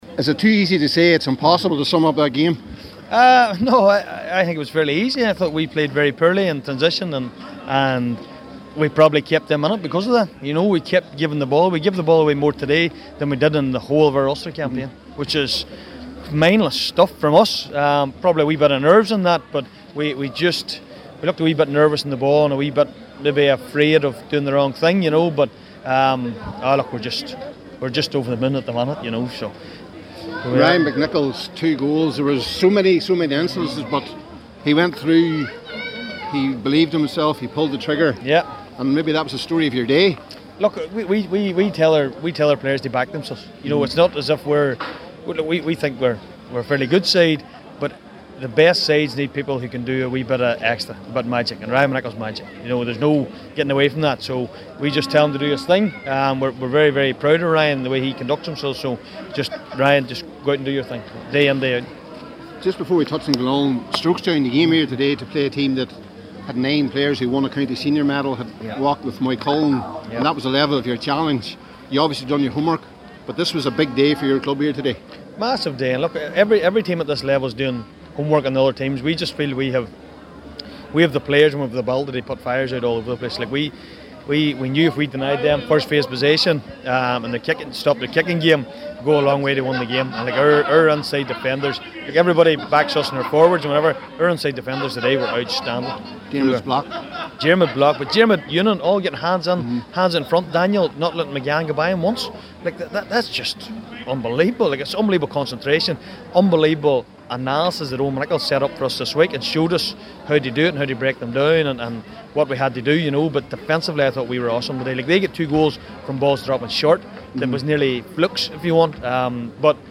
After the game today,